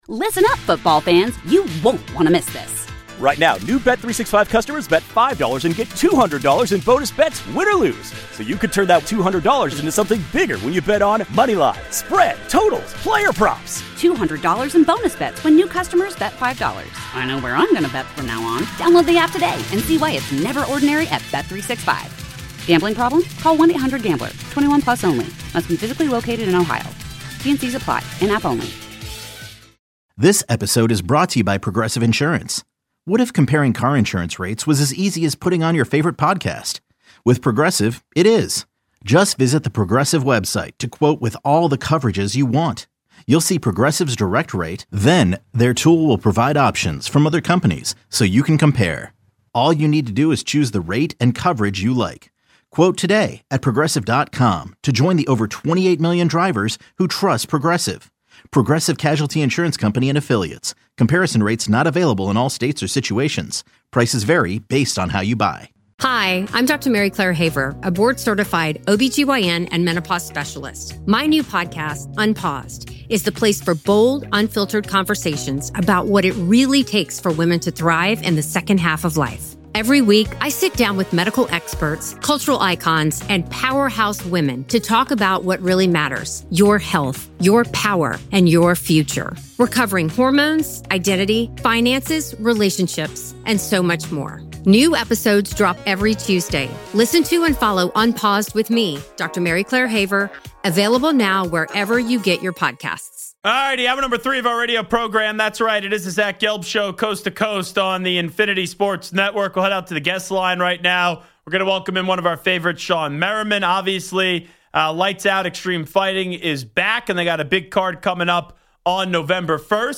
Shawne Merriman Interview (Hour 3) (; 29 Oct 2025) | Padverb
Shawne Merriman, former NFL pass rusher, joins Zach Gelb.